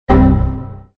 دانلود آهنگ خطای ویندوز 1 از افکت صوتی اشیاء
دانلود صدای خطای ویندوز 1 از ساعد نیوز با لینک مستقیم و کیفیت بالا
جلوه های صوتی